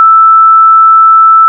Misc (2): coin_collect, level_up
**⚠  NOTE:** Music/SFX are PLACEHOLDERS (simple tones)
level_up.wav